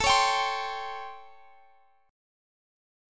Listen to A7#9 strummed